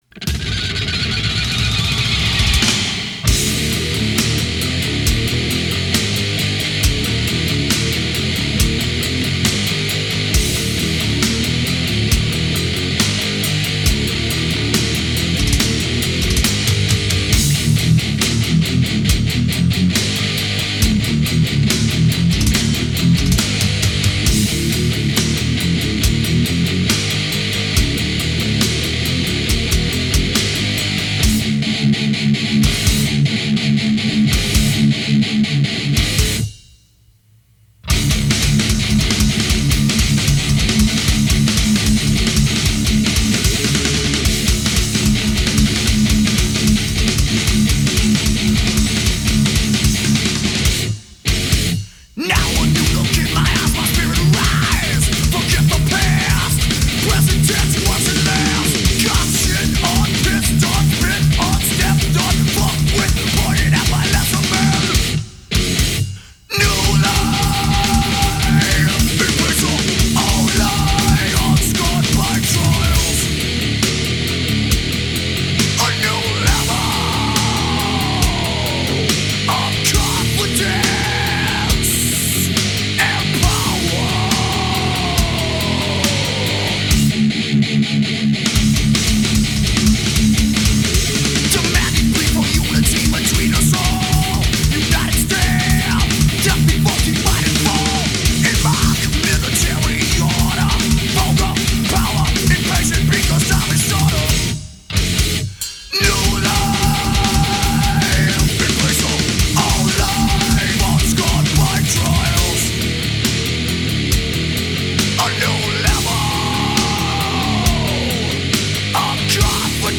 1992 Genres: Groove Metal, Thrash Metal